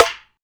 SNARE.57.NEPT.wav